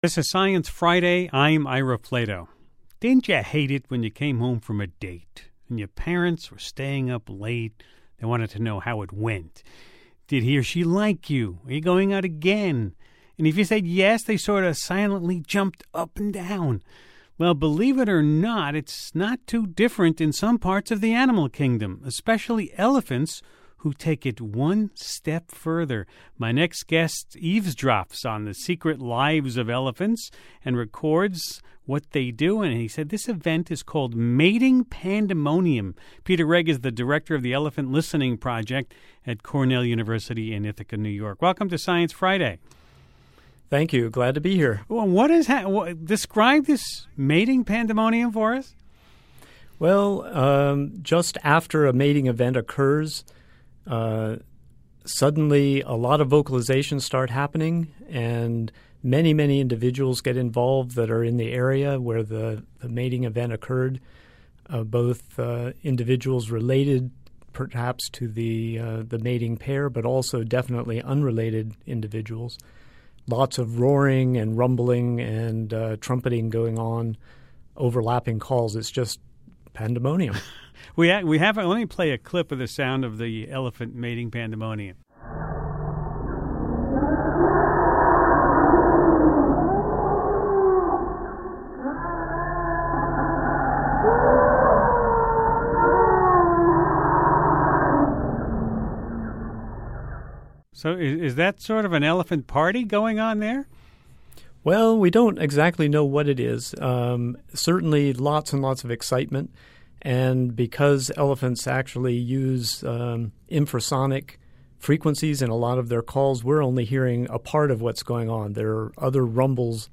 In a “mating pandemonium” event, a group of elephants roar after a pair of elephants mate.